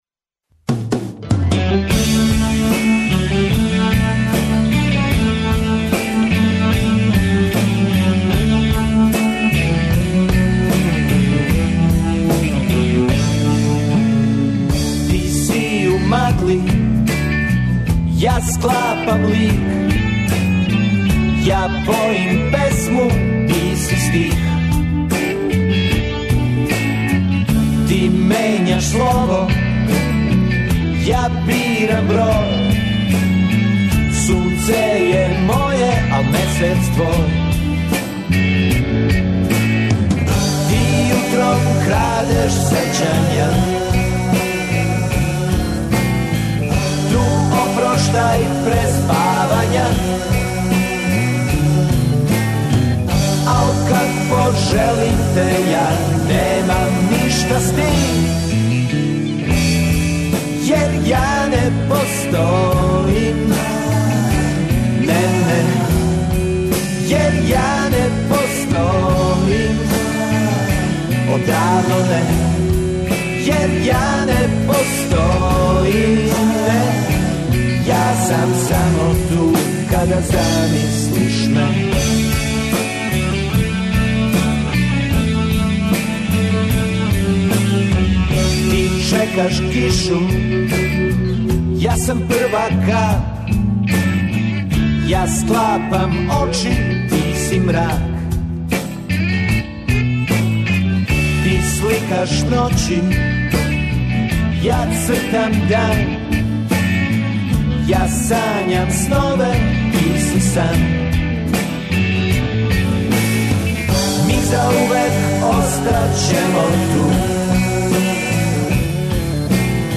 Емисију емитујемо уживо из Љубовије, представићемо вам културни живот овога града.